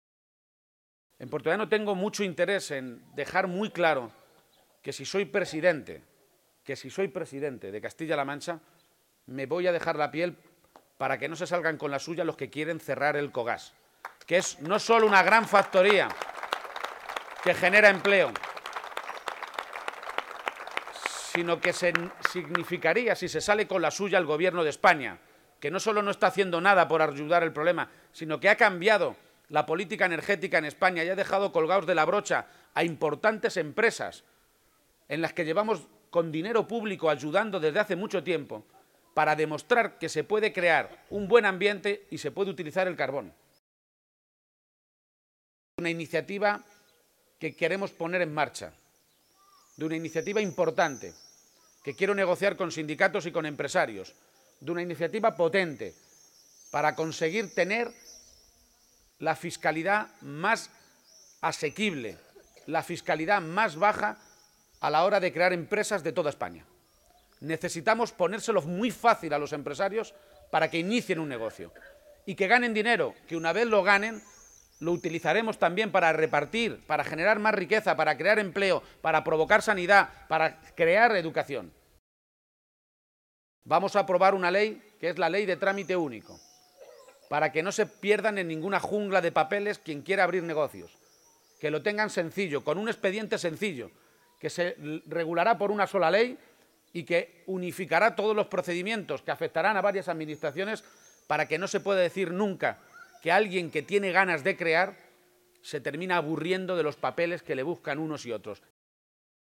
García-Page se pronunciaba de esta manera esta mañana, en el arranque de la segunda jornada de la campaña electoral, que iniciaba en la localidad toledana de Orgaz, donde ha acompañado al alcalde y candidato a la reelección, Tomás Villarrubia.